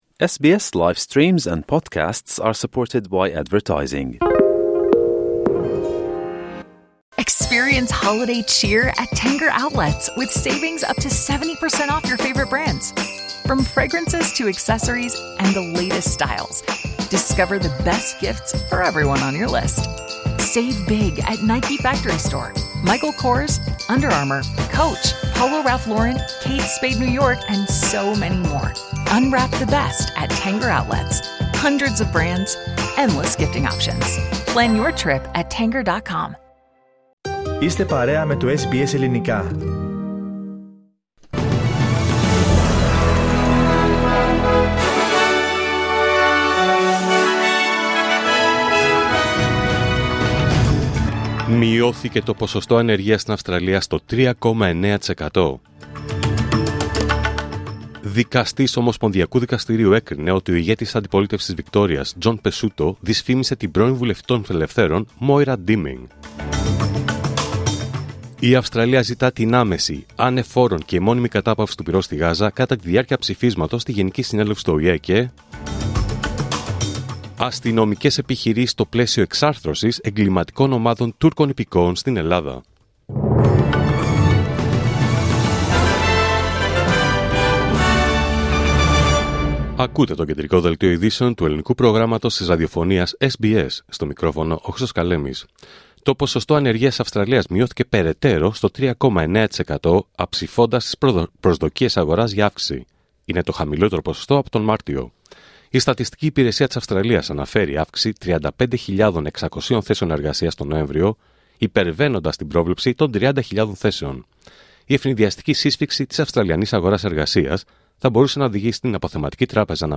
Δελτίο Ειδήσεων Πέμπτη 12 Δεκέμβριου 2024